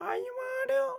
Audio / SFX / Characters / Voices / PigChef / PigChef_08.wav